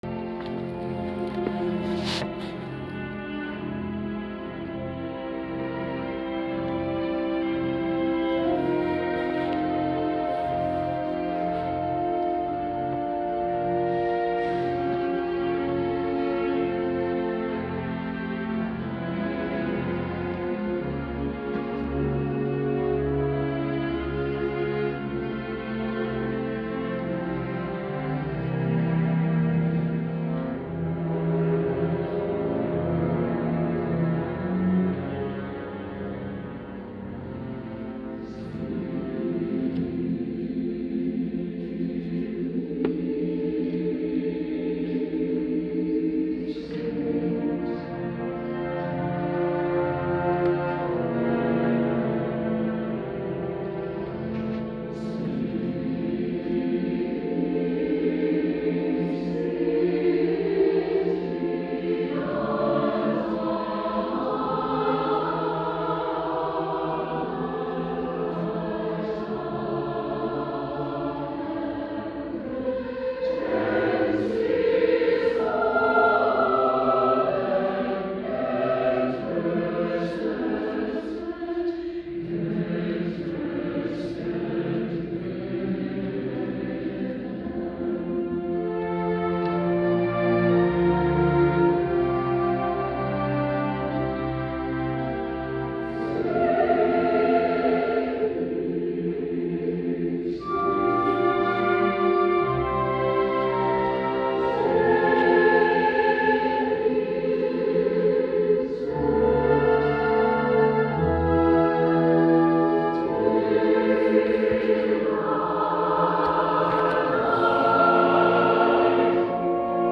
Sounds & Videos of the Chorus Click on the links below to watch and/or listen to the partial or full performances of the Naperville Chorus.